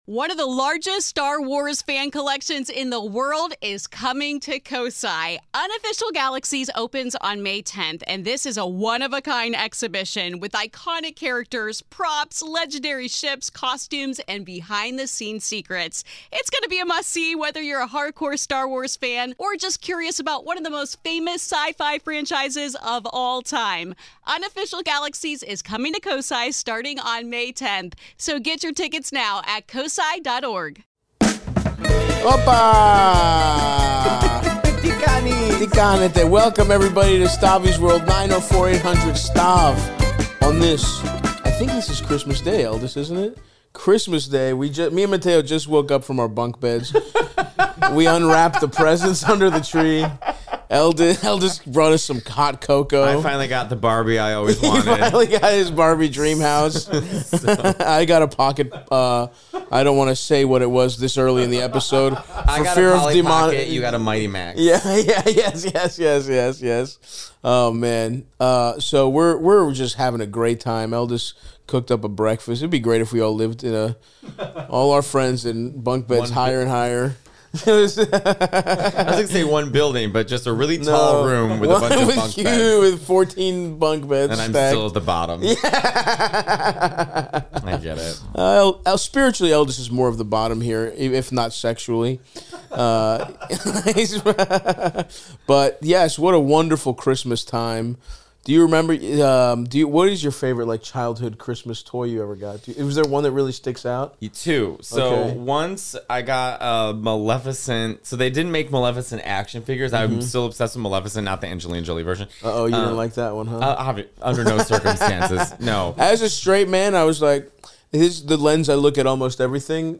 Friend of the show Matteo Lane returns for a special Christmas edition of Stavvy's World to talk about the perfect Xmas gift his aunt once got him as a child, serenade everyone to his beautiful rendition of a Christmas classic, and more. Matteo and Stav help callers including a man who's going to counseling with his mother-in-law but wants his refusing wife to attend, and a guy who's got the wrong idea about his wife's friend.